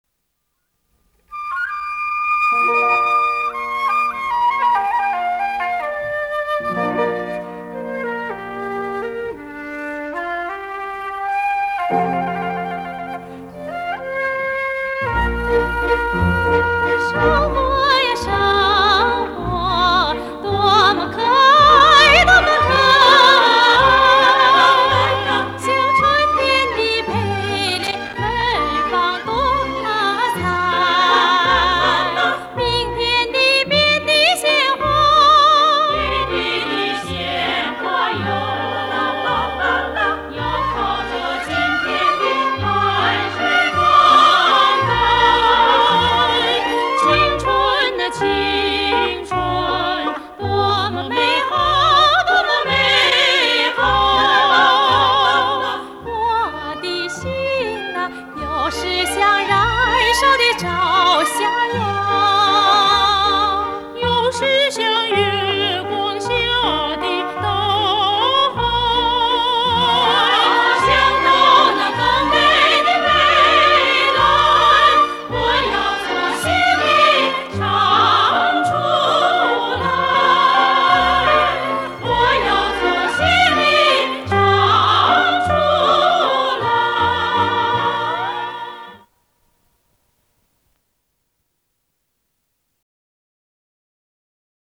主题歌